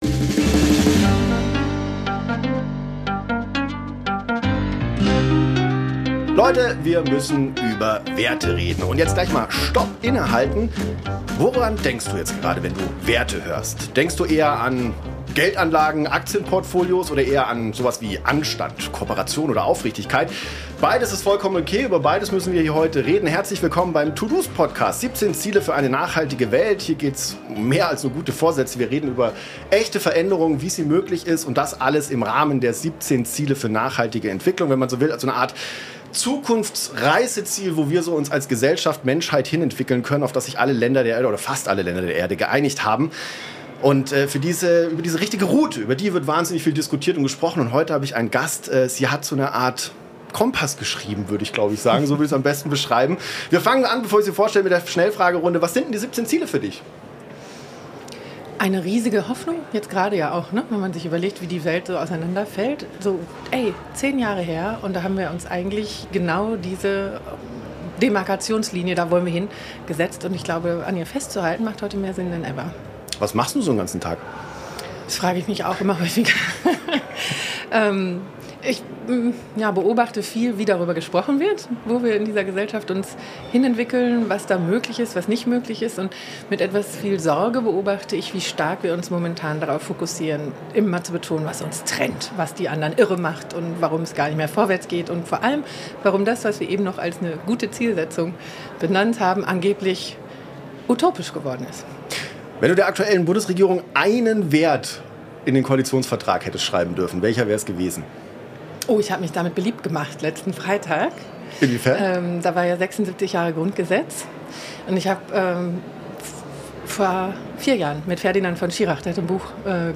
Es geht um Wahrhaftigkeit in der Politik, neue Wohlstandsdefinitionen und den blinden Fleck des Bruttoinlandsprodukts. Die beiden diskutieren, warum ökonomisches Denken allein nicht ausreicht, um die Polykrisen – Klima, Krieg, Autoritarismus, Biodiversität – erfolgreich zu bewältigen. Ein inspirierender Dialog über Systemgrenzen, Möglichkeitsräume und die Rolle jeder und jedes Einzelnen.